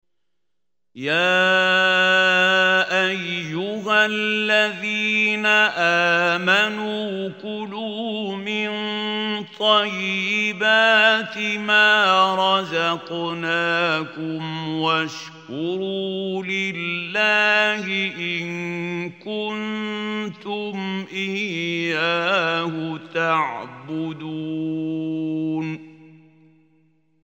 القارئ محمود خليل الحصري